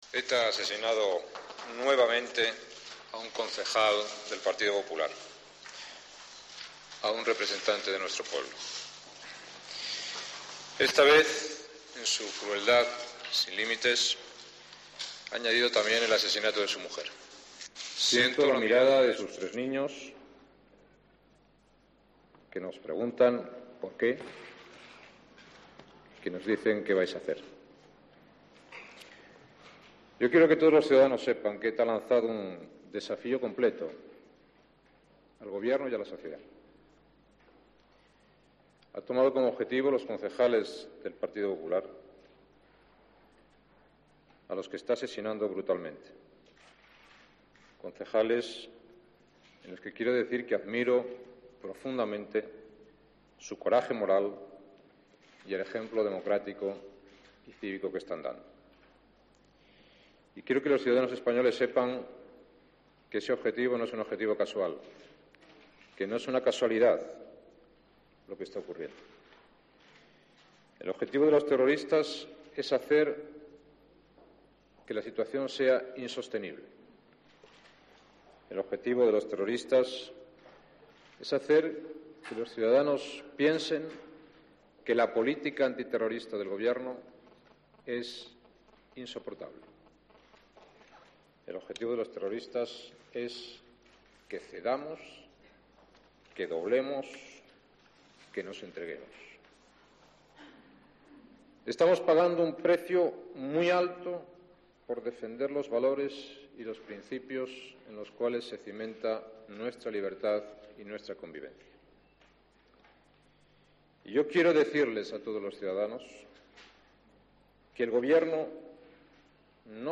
Jose María Aznar, presidente del Gobierno, tras el asesinato de Jiménez Becerril y su esposa